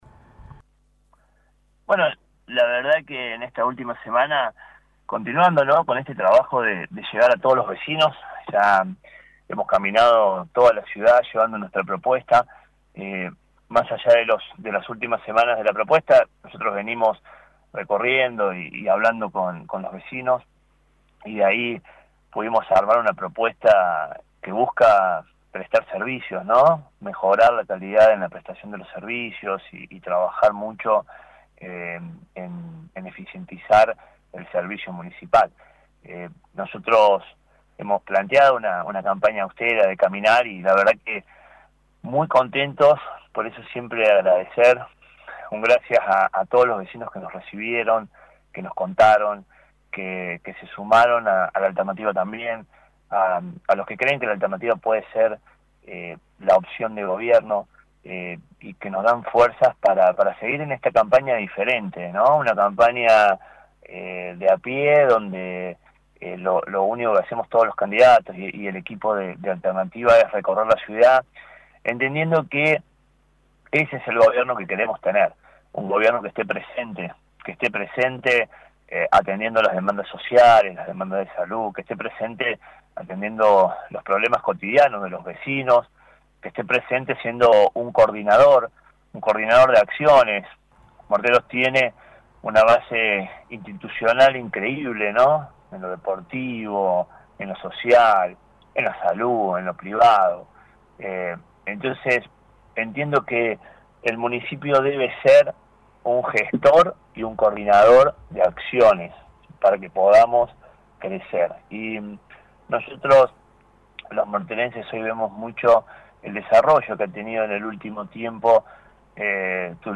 ENTREVISTA Y MENSAJE FINAL